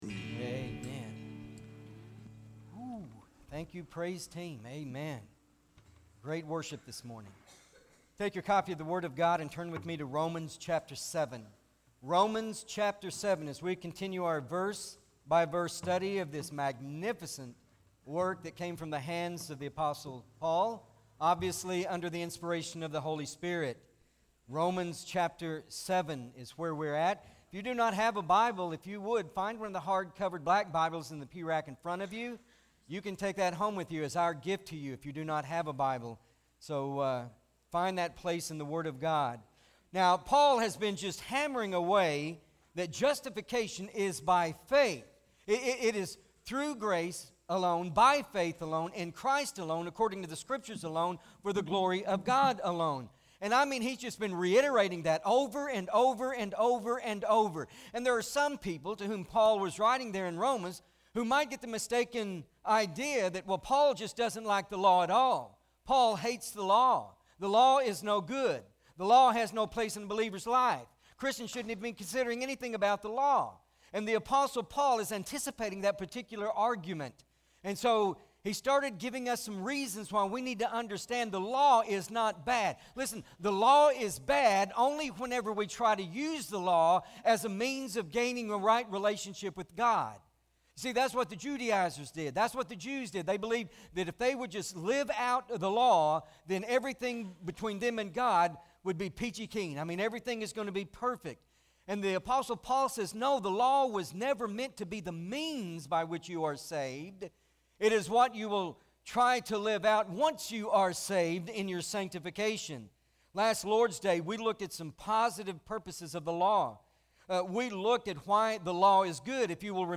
Romans Revealed- The Purpose of the Law- Pt.2 MP3 SUBSCRIBE on iTunes(Podcast) Notes Sermons in this Series Romans 7: 7-13 Not Ashamed!